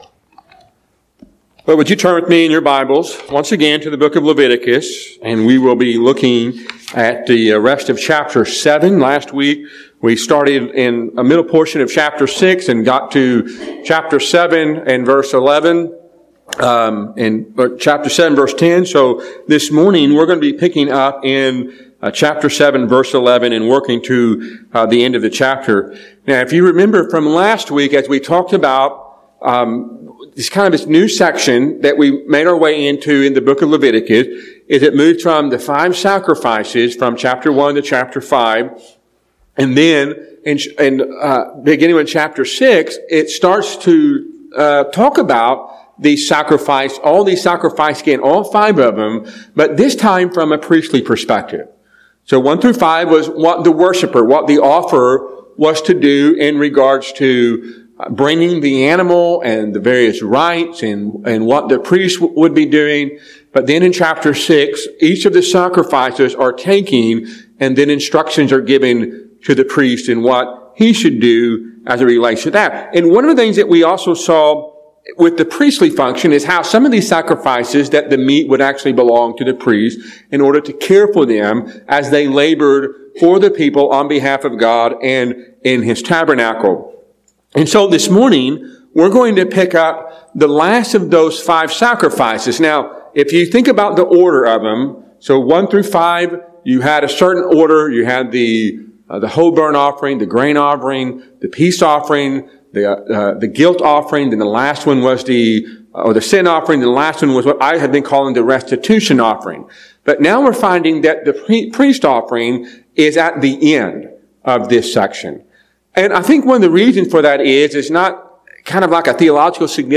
A sermon from Leviticus 7:11-38.